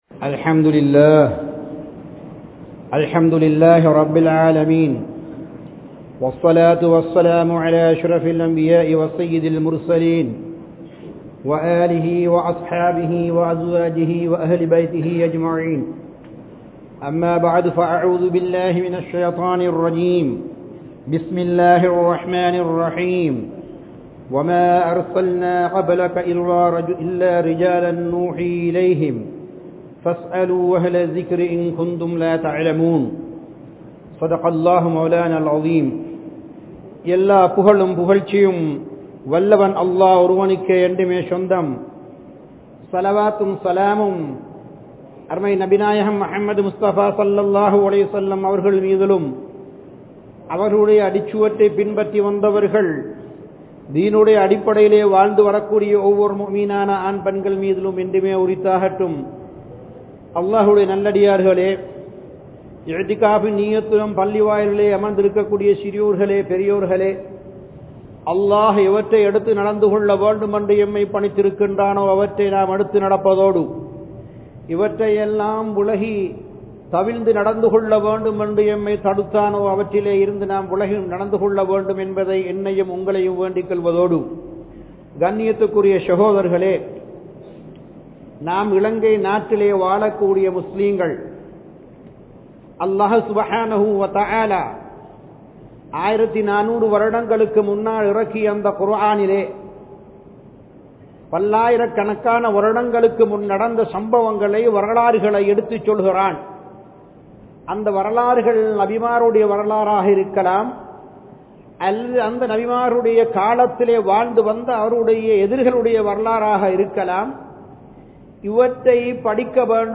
Ilangai Muslimkal (இலங்கை முஸ்லிம்கள்) | Audio Bayans | All Ceylon Muslim Youth Community | Addalaichenai